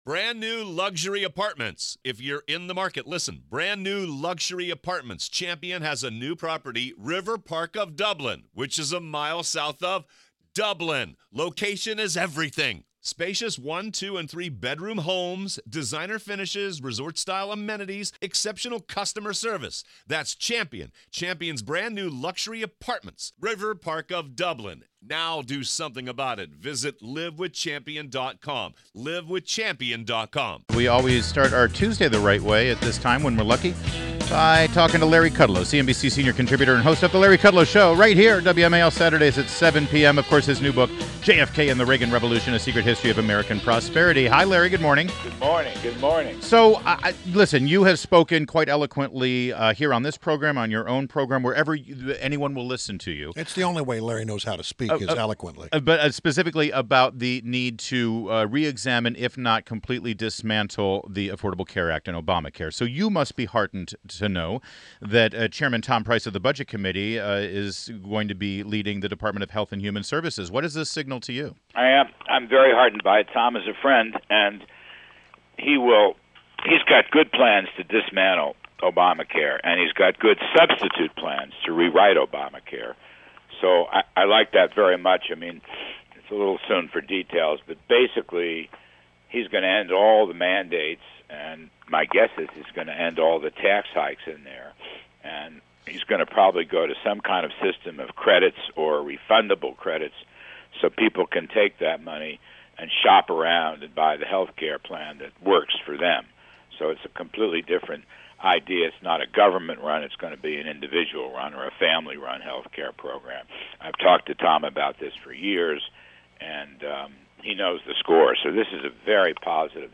WMAL Interview - LARRY KUDLOW - 11.29.16